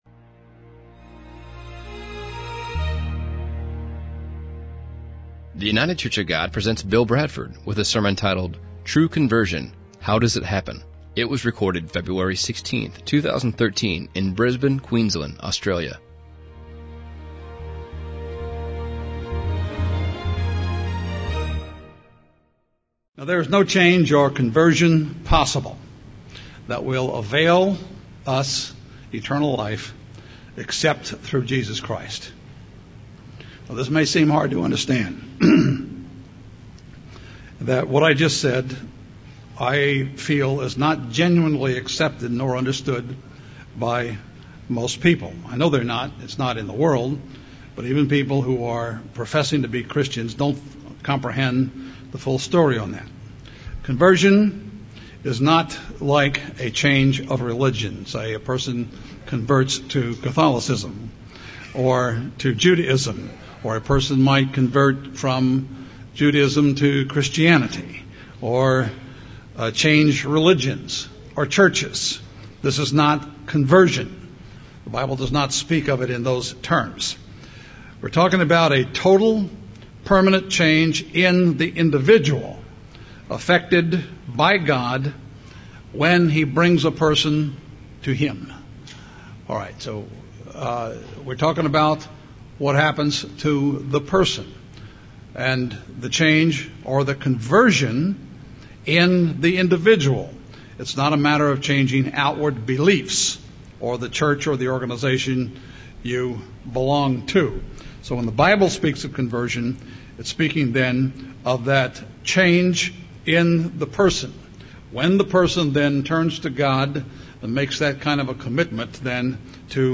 This sermon discusses how true conversion happens.